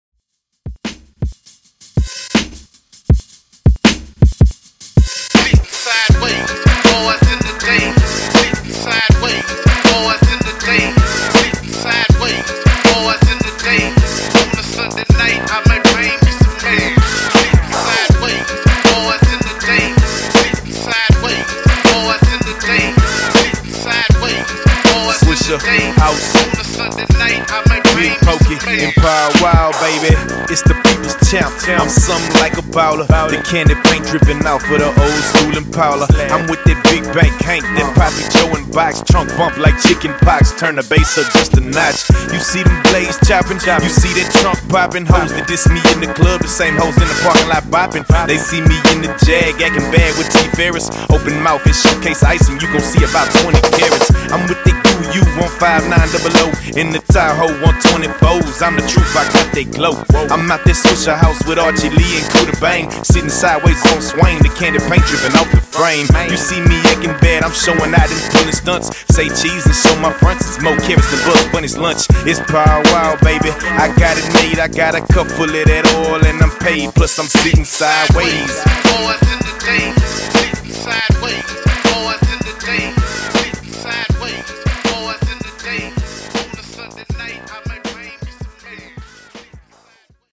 Old School Redrum)Date Added